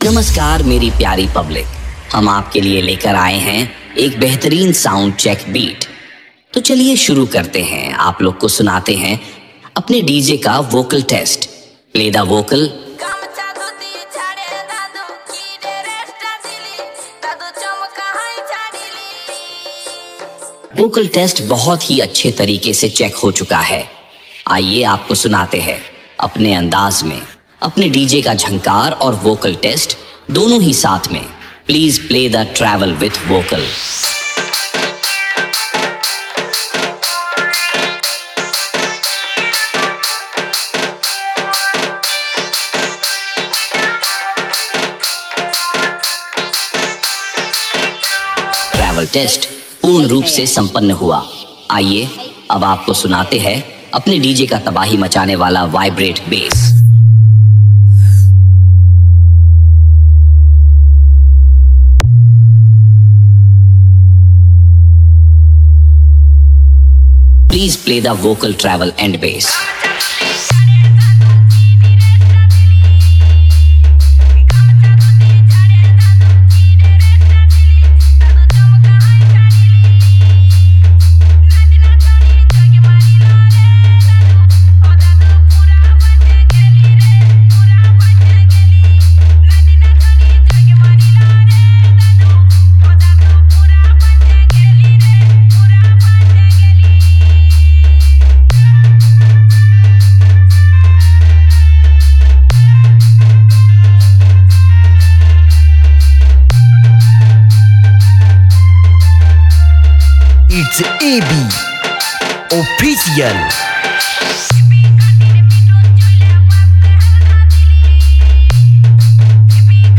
Dj Remixer
Sound Check Dj Remix